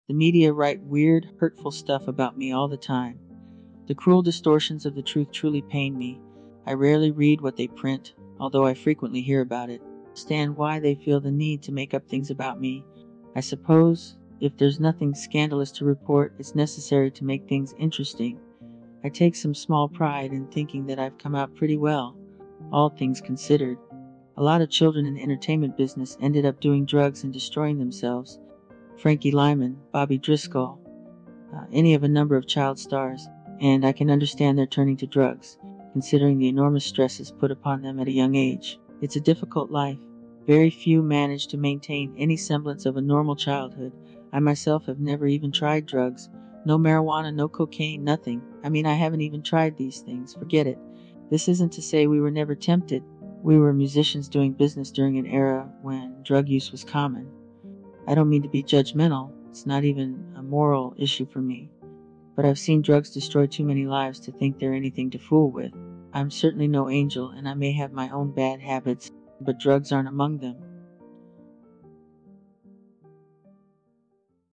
Pt.7 audio reading from Michael sound effects free download
Pt.7 audio reading from Michael Jackson book Moonwalker